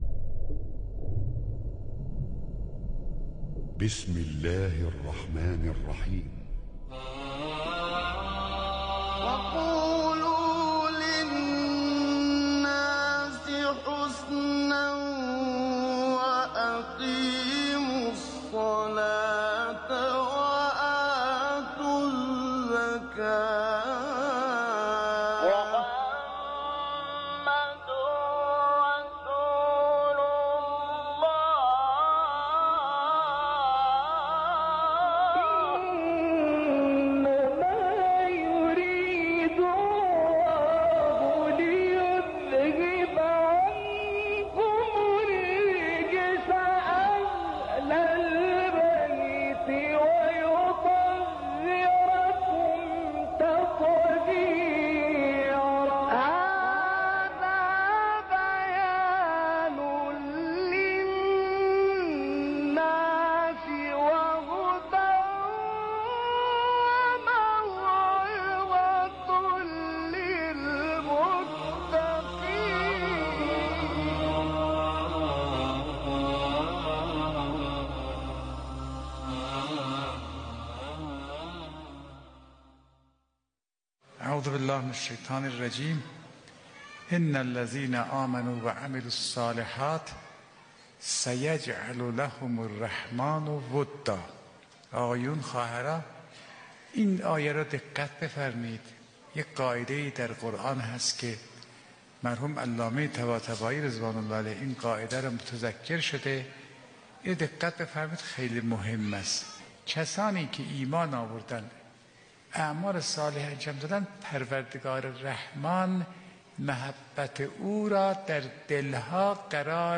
سخنرانی آیت الله فاطمی نیا در مراسم چهلم سردار شهید قاسم سلیمانی